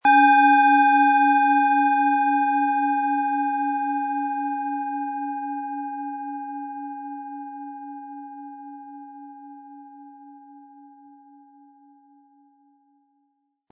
Planetenschale® Heiter Sein & Geistige Wachheit mit Merkur, Ø 12,6 cm, 180-260 Gramm inkl. Klöppel
Planetenton 1
Im Sound-Player - Jetzt reinhören können Sie den Original-Ton genau dieser Schale anhören.